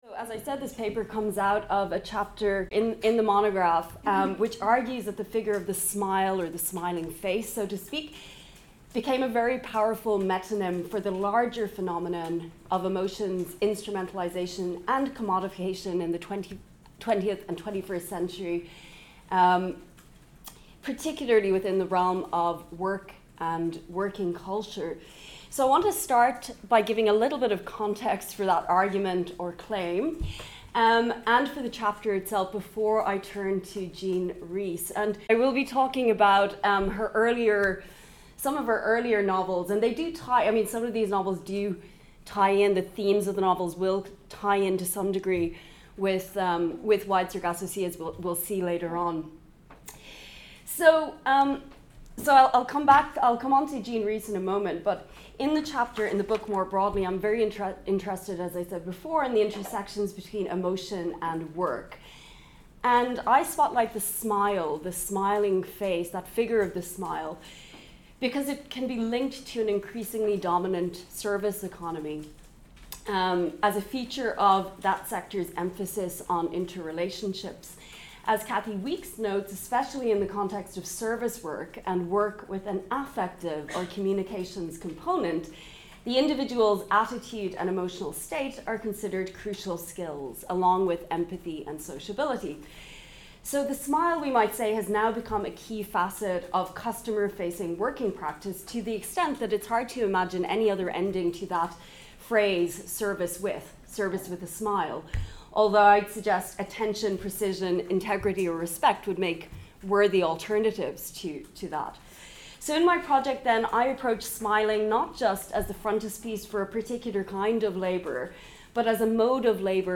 [Conférence] In this talk